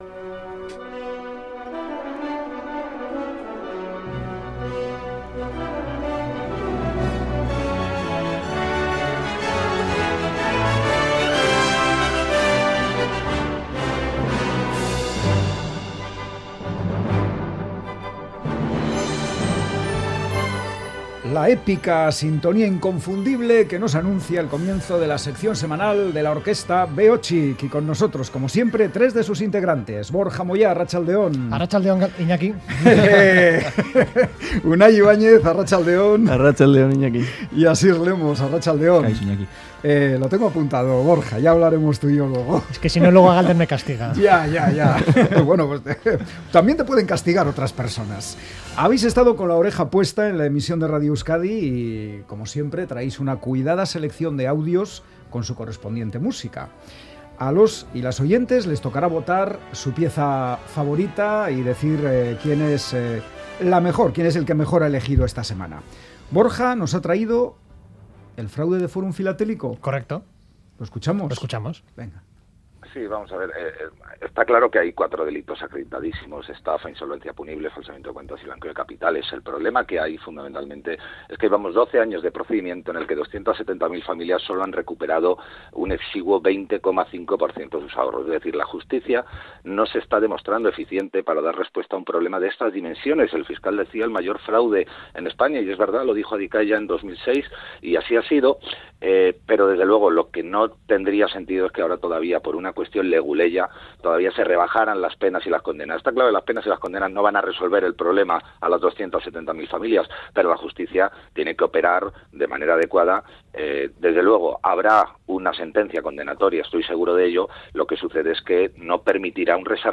ponen banda sonora a diversos momentos de la programación de Radio Euskadi